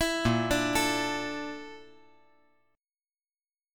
A#M7b5 Chord
Listen to A#M7b5 strummed